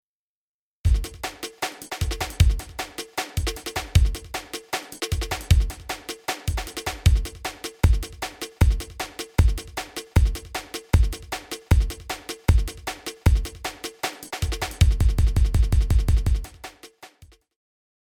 ループループ
同じ箇所を繰り返す演奏法。